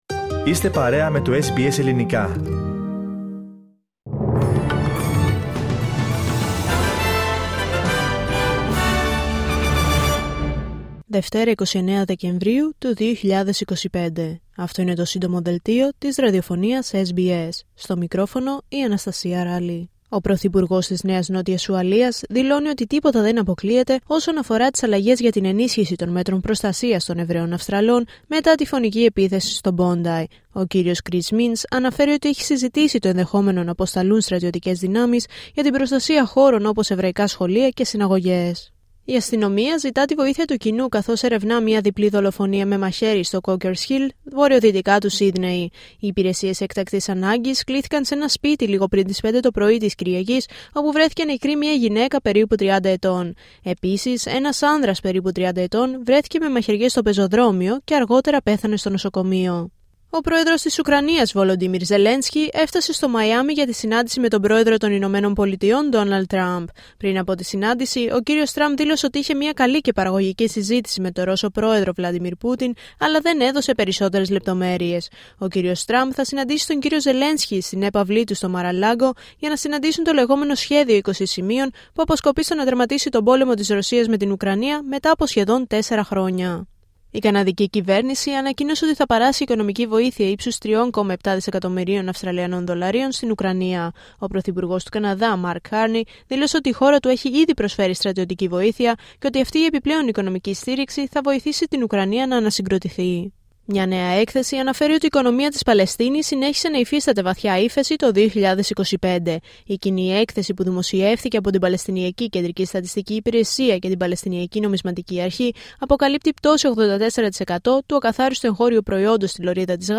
H επικαιρότητα έως αυτή την ώρα στην Αυστραλία, την Ελλάδα, την Κύπρο και τον κόσμο στο Σύντομο Δελτίο Ειδήσεων της Δευτέρας 29 Δεκεμβρίου 2025.